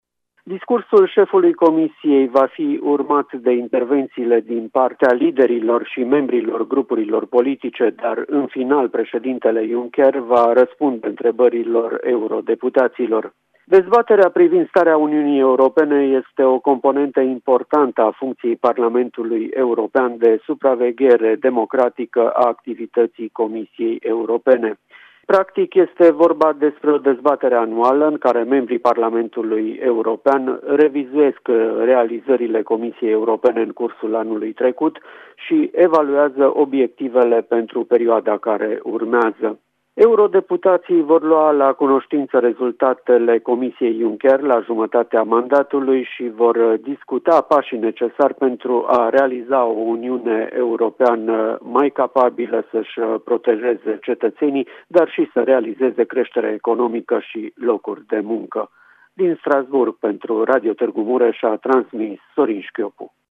O corespondență de la Strasbourg transmisă de